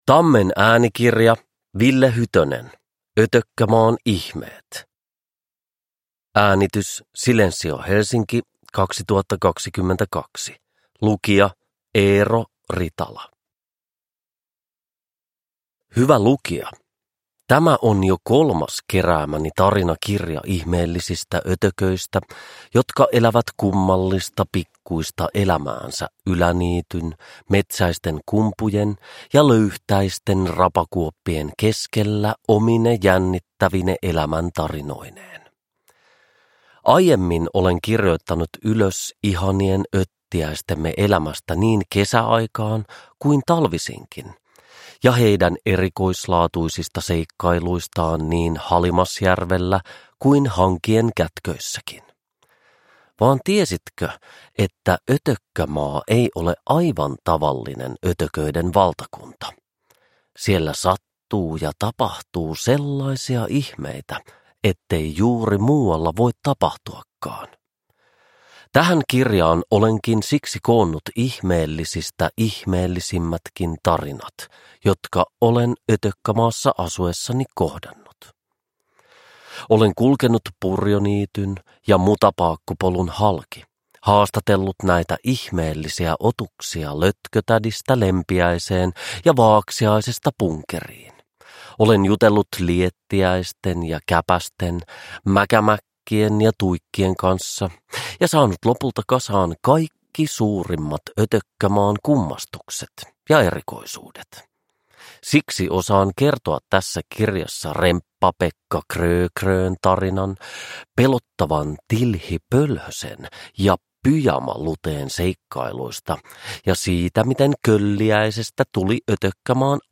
Ötökkämaan ihmeet – Ljudbok – Laddas ner